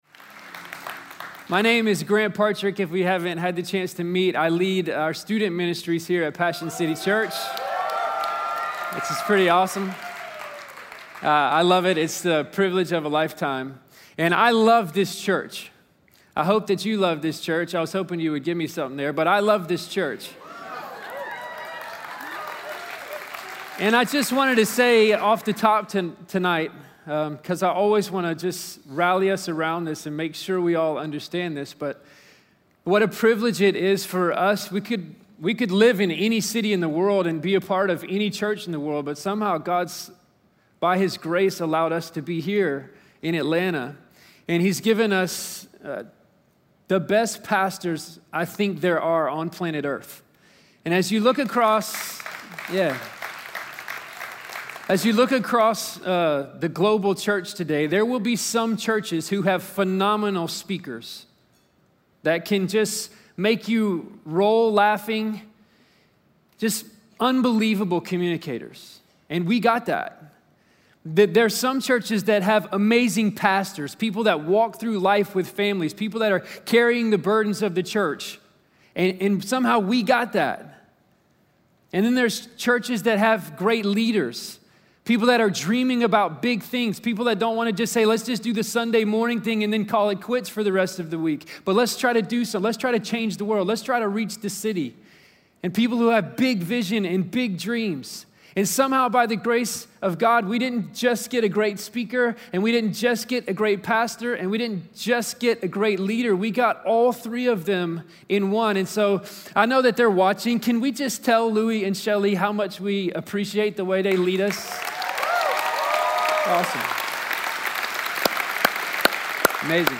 Christianity, Passion, Religion & Spirituality, Passionconferences, Messages, Louiegiglio, Sermons, Passioncitychurch, Church